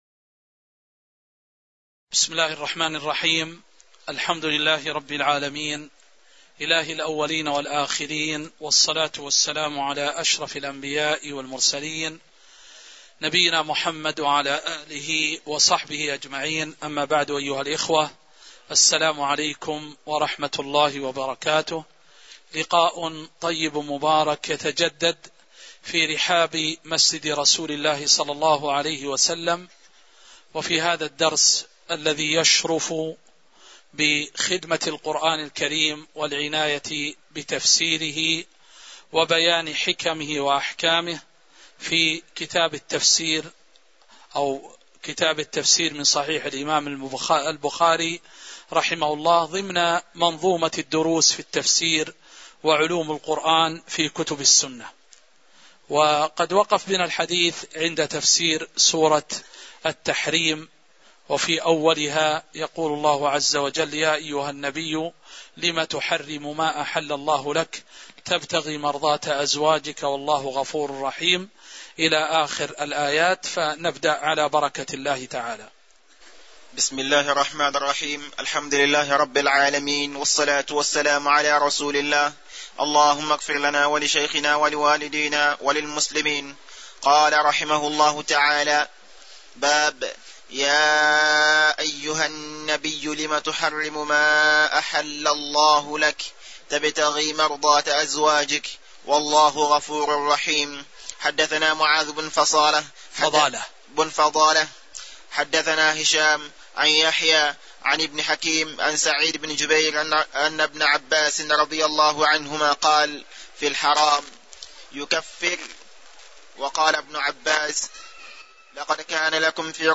تاريخ النشر ٢٩ ربيع الثاني ١٤٤٠ هـ المكان: المسجد النبوي الشيخ